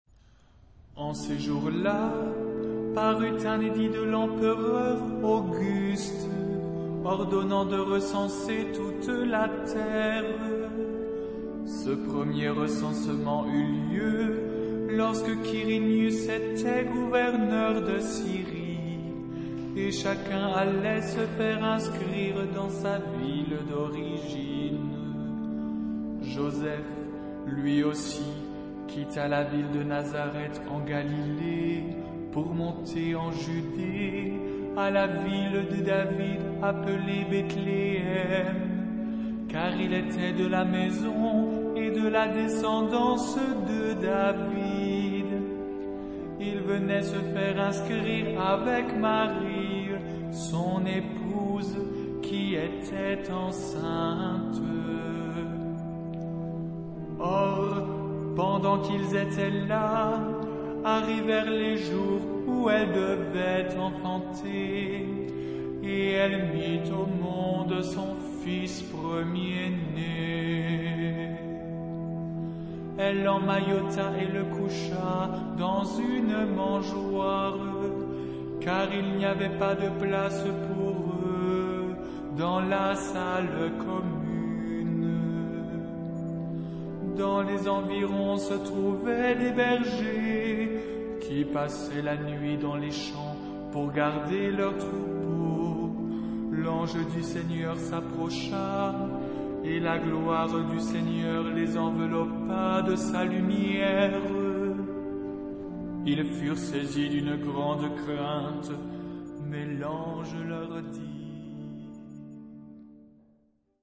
Género/Estilo/Forma: Recitativo
Carácter de la pieza : energico
Tipo de formación coral: unisson
Solistas : Baryton (1)  (1 solista(s) )
Instrumentación: Organo
Tonalidad : mixolidio